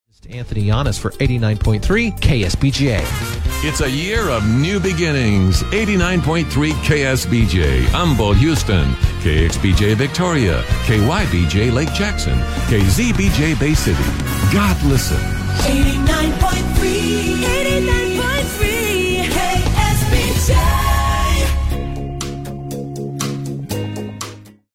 KSBJ Top of the Hour Audio: